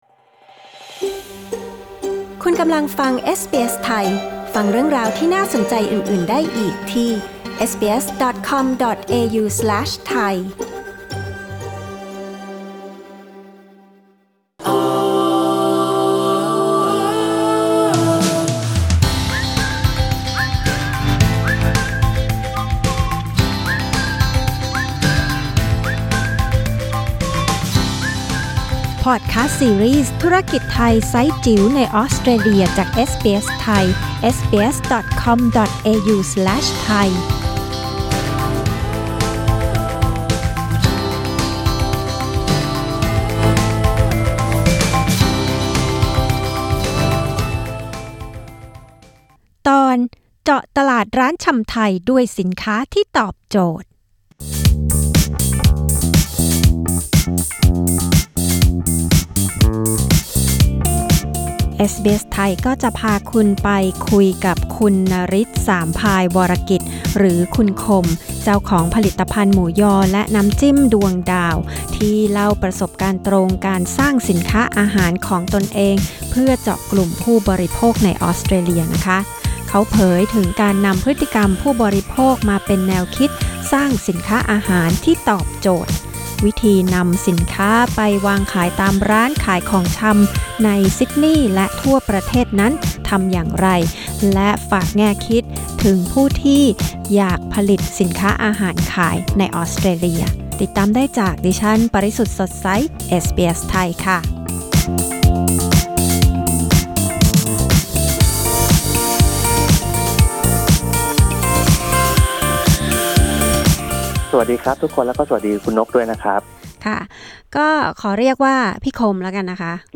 กดปุ่ม 🔊 ที่ภาพด้านบนเพื่อฟังสัมภาษณ์เรื่องนี้